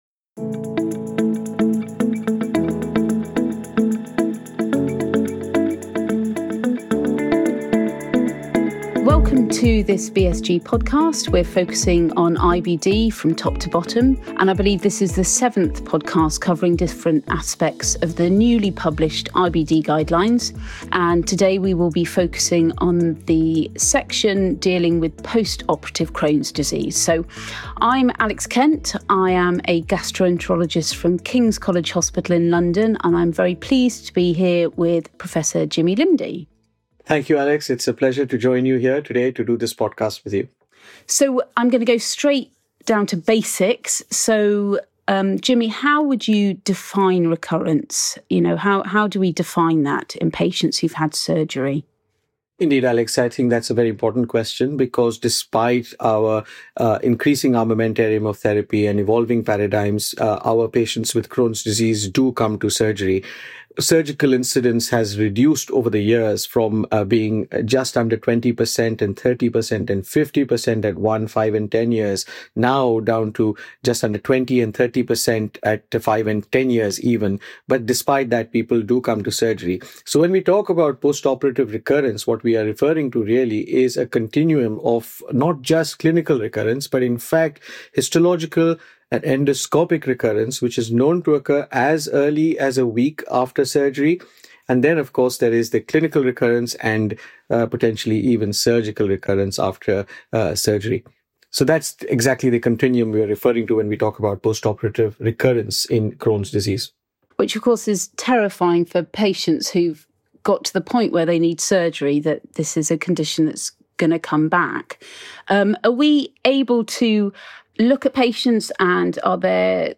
Together, they discuss the continuum of post-operative recurrence from histological and endoscopic changes to clinical and surgical relapse. They highlight key patient, disease, and surgical risk factors, including smoking and aggressive disease phenotypes.